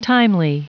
Prononciation du mot timely en anglais (fichier audio)
Prononciation du mot : timely